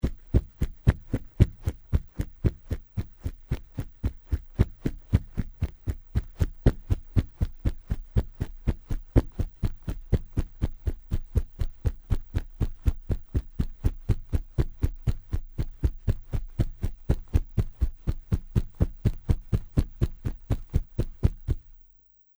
土路上的奔跑－偏低频－YS070525.mp3
通用动作/01人物/01移动状态/土路/土路上的奔跑－偏低频－YS070525.mp3
• 声道 立體聲 (2ch)